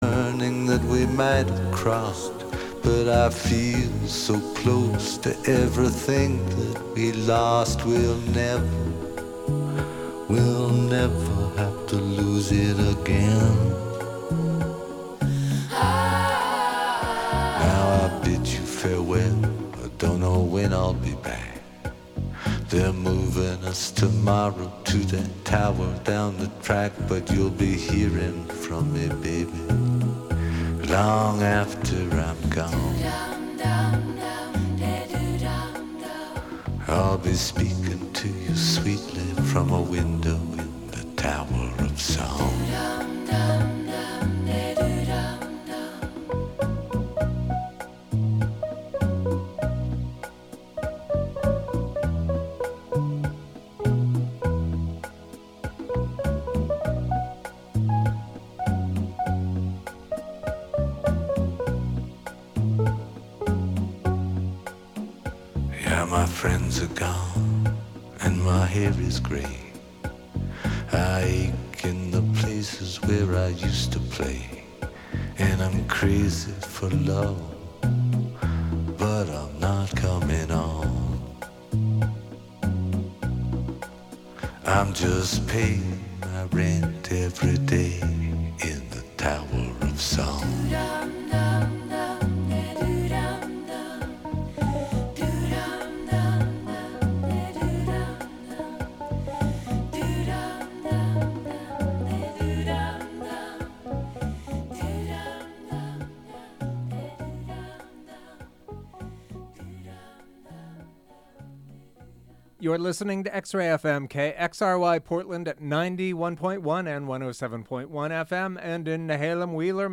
Join the hunt for lost gems and new treasures every Wednesday at 7pm. We serve indie rock, global psychedelica, lo-fi, art punk, a particular sort of americana, Portland-centric, international pop, folkishness, and real rock and good radio.